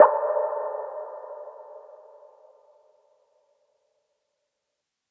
Hit_Blocka.wav